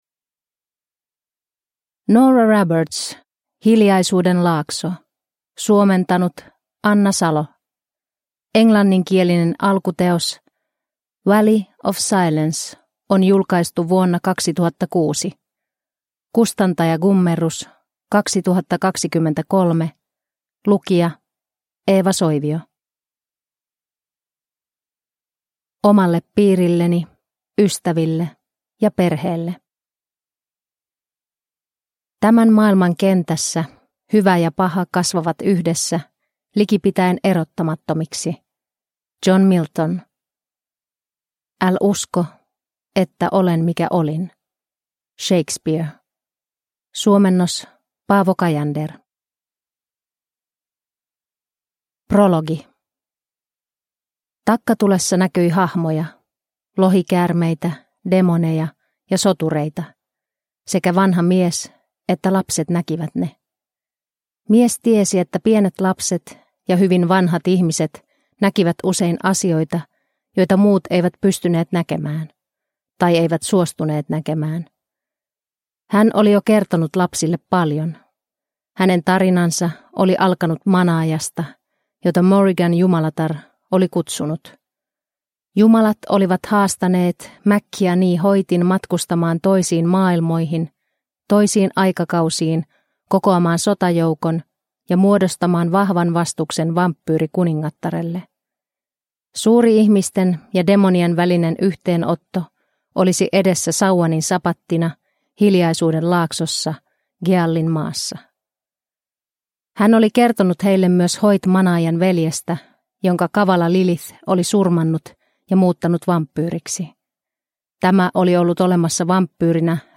Hiljaisuuden laakso – Ljudbok – Laddas ner